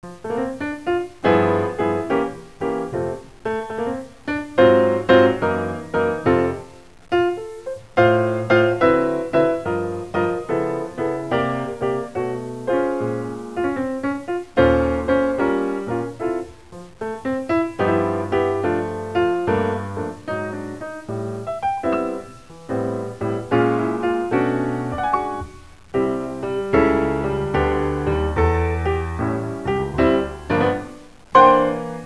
beboppiano.wav